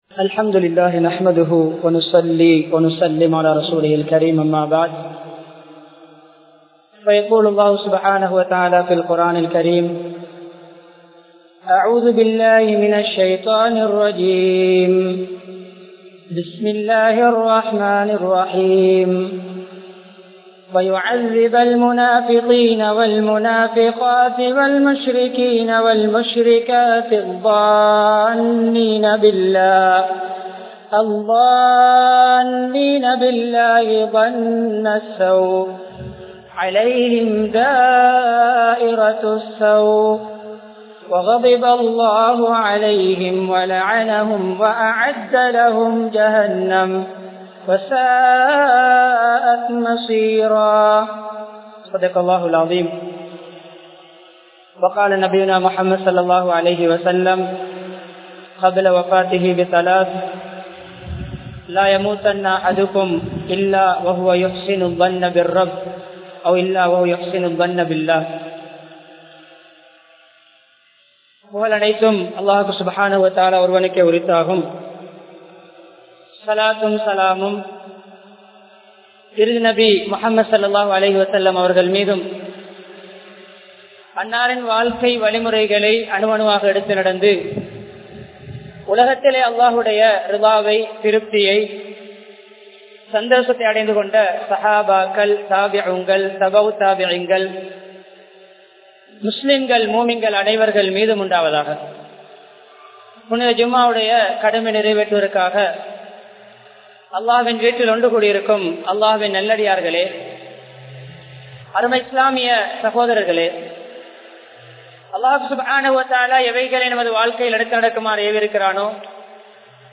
Allah`vudan Nallennam Vaipoam (அல்லாஹ்வுடன் நல்லெண்ணம் வைப்போம்) | Audio Bayans | All Ceylon Muslim Youth Community | Addalaichenai